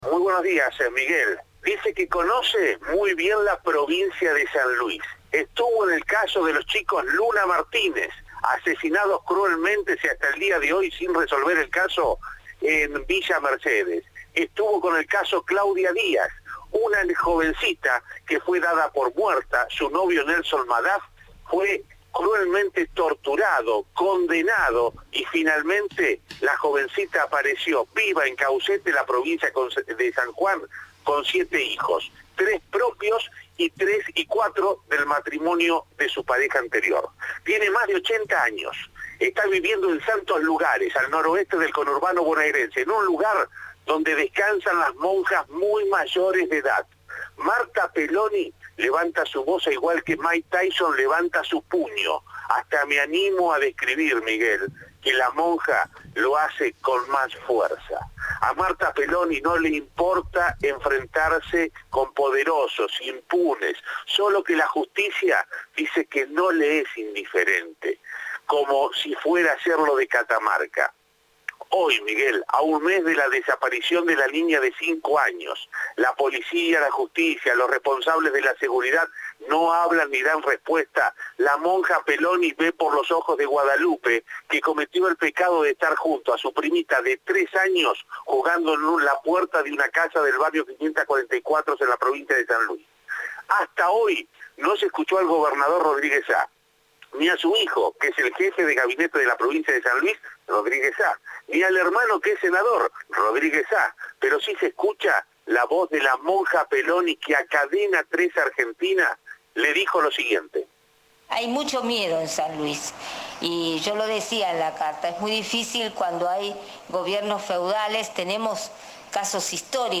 "Es muy difícil que haya justicia cuando hay gobierno feudales", dijo a Cadena 3 la religiosa.